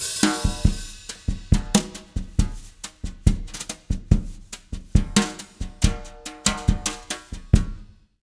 140BOSSA03-R.wav